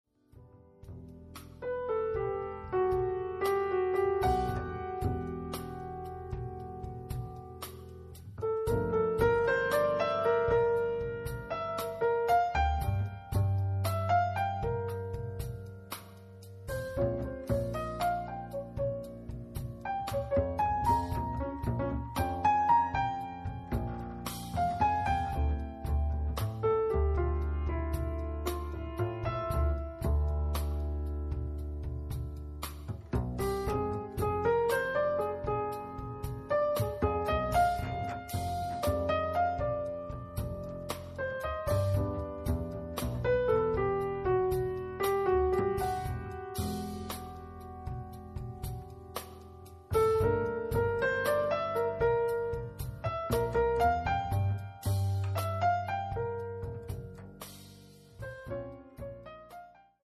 contrabbasso
pianoforte
batteria